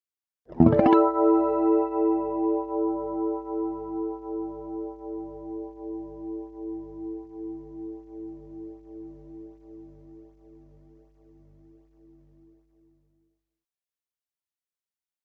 Electric Guitar Harmonics On 5th Fret With Chorus